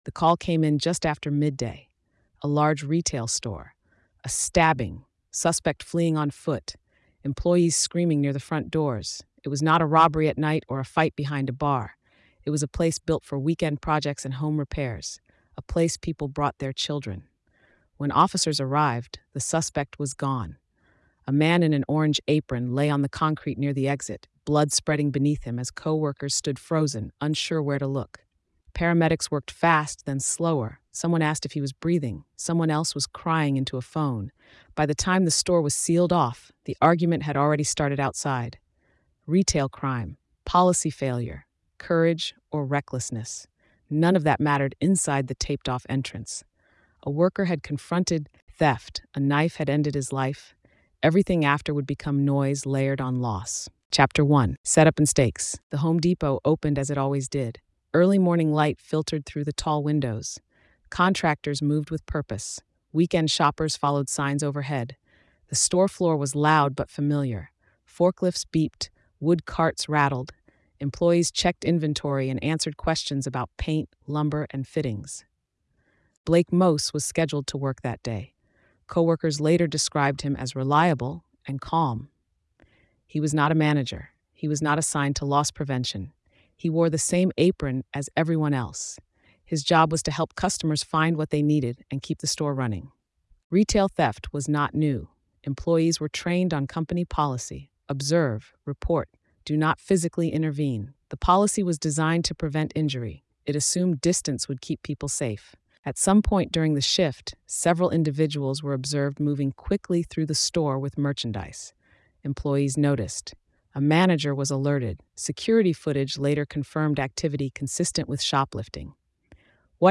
Told in a neutral, gritty documentary voice, the story follows the incident from the moment of confrontation through the investigation, arrest, and broader public fallout. The narrative focuses on verified facts, investigative pressure, and real-world consequences, placing the tragedy within the wider debate over retail crime, employee safety, and corporate non-intervention policies without speculation or sensationalism.